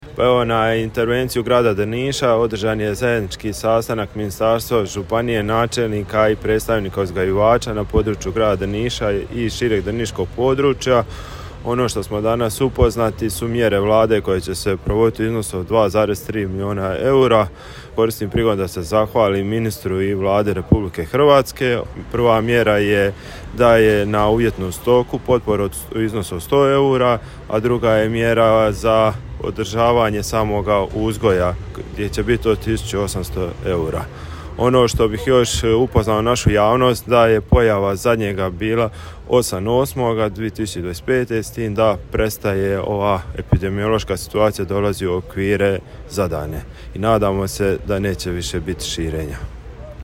Gradonačelnik Drniša Tomislav Dželalija zadovoljan je sastankom te nam je kazao: